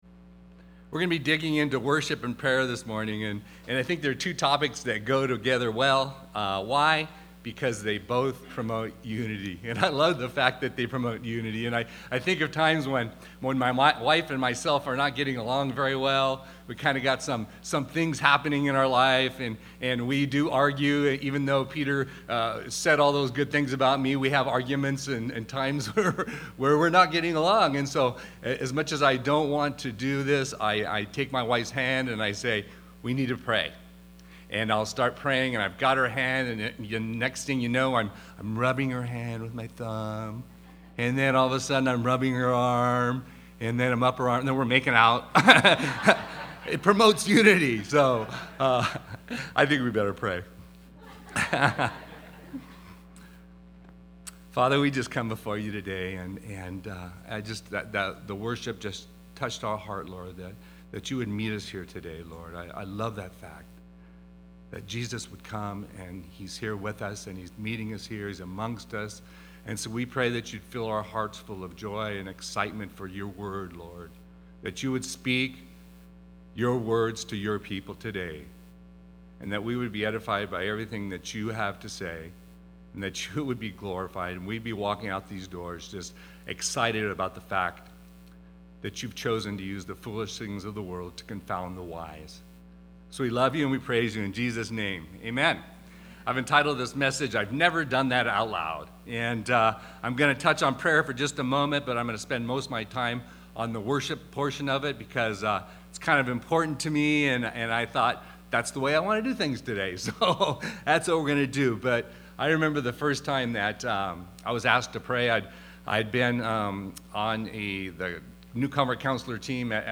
Series: Guest Speaker
Psalm 147:1-7 Service: Sunday Morning Psalm 147:1-7 « For Such a Time As This Sold Out